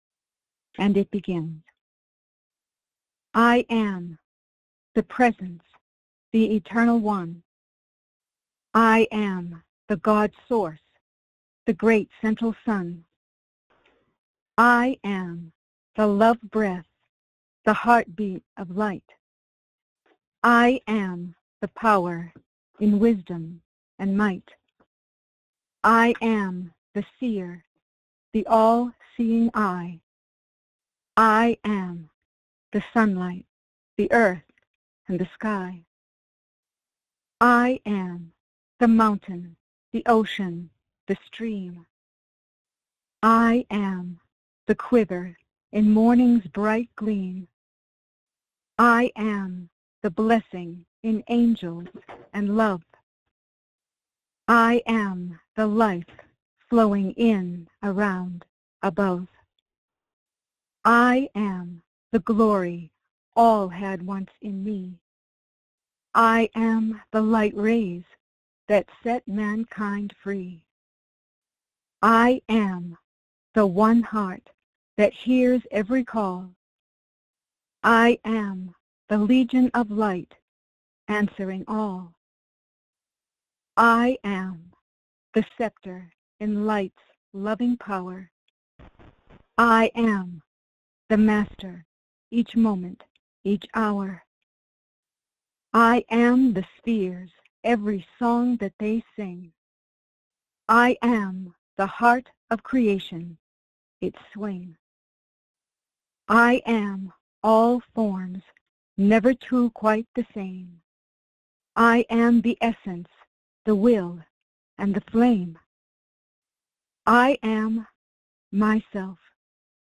Join in group meditation with master Saint Germain.